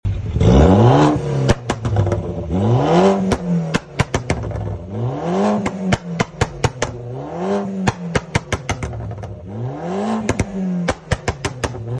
Bmw 116i stage 2 tuned sound effects free download
Bmw 116i stage 2 tuned Pops&bangs, dcat and dual exhaust system.